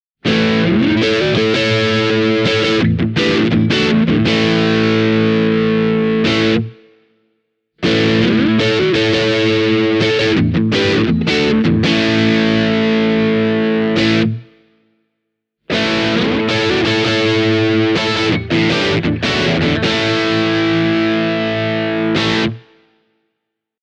Driven into distortion Squier’s Fideli’Trons satisfy with a healthy dose of bite and snarl:
squier-cabronita-telecaster-e28093-overdriven.mp3